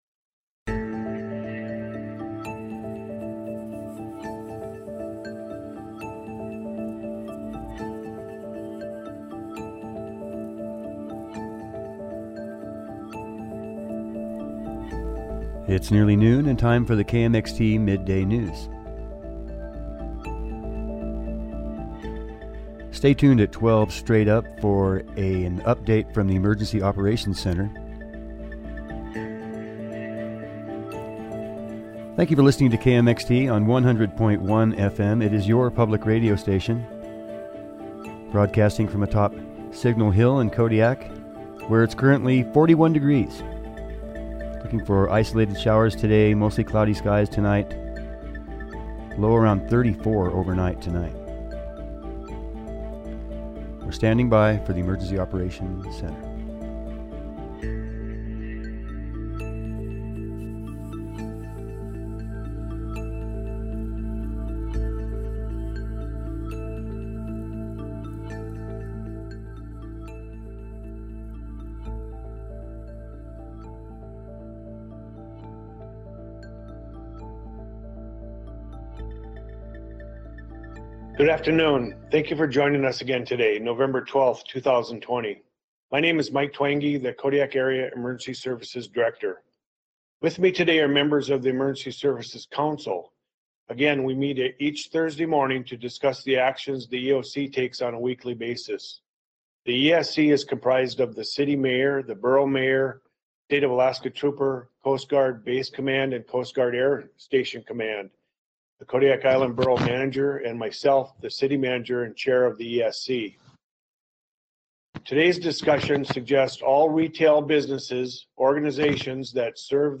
Newscast–Thursday, November 12, 2020